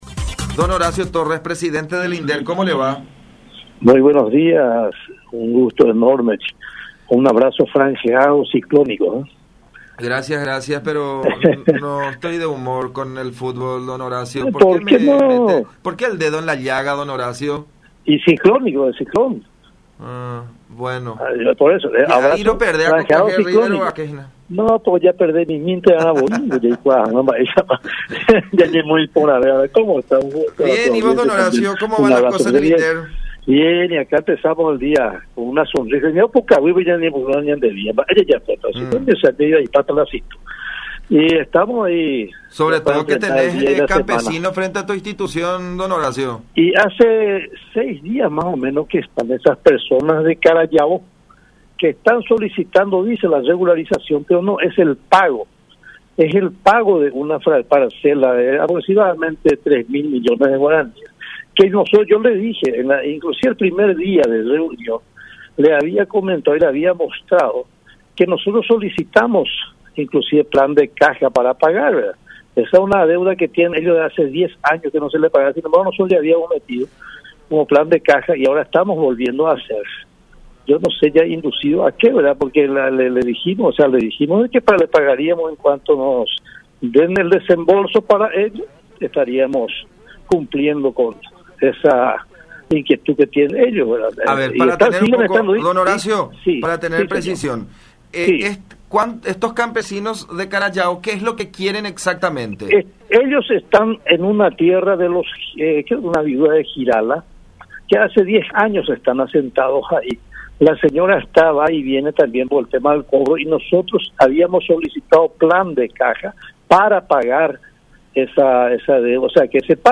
Desde la institución informan que han solicitado plan de caja al Ministerio de Hacienda para la compra de las tierras, consistente en un monto de G. 3.000 millones, según explicó el presidente de la institución, Horacio Torres, en contacto con La Unión, quien espera el desembolso para proceder al pago.
07-Horacio-Torres-Presidente-del-INDERT-sobre-situación-de-tierras-reclamadas-por-campesinos.mp3